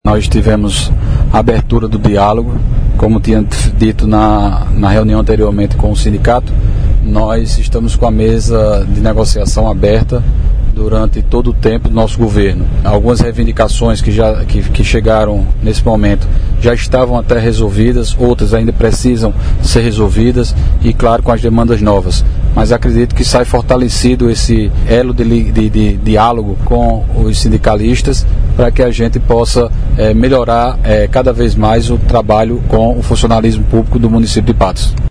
Fala do prefeito Dinaldinho Wanderley